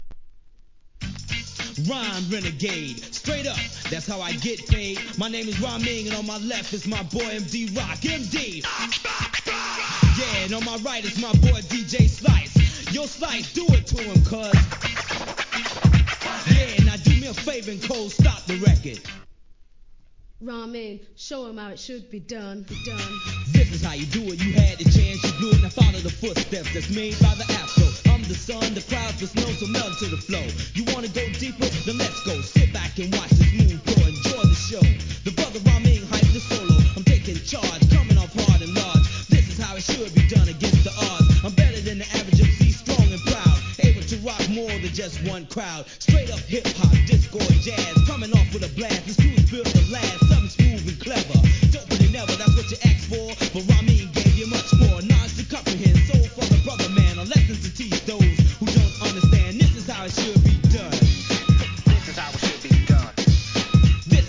HIP HOP/R&B
マイアミ産ミドルSCHOOL!!!